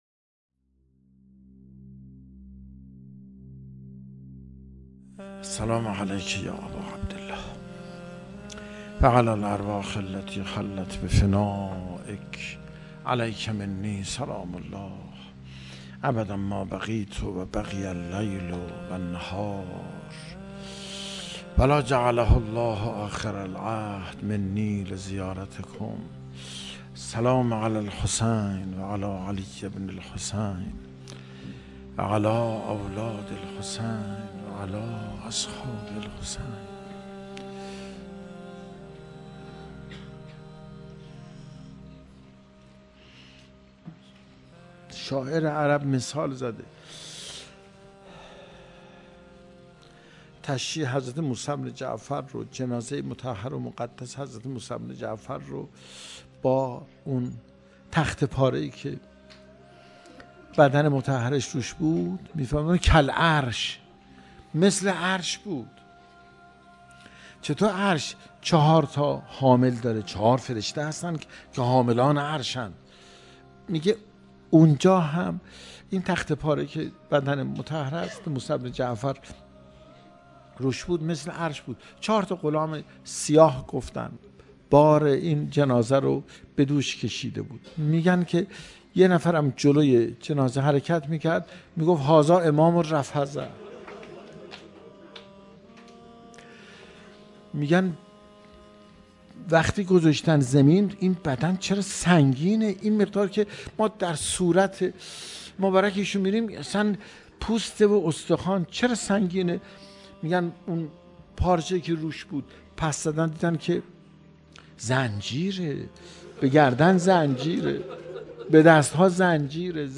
روضه امام موسی کاظم(ع)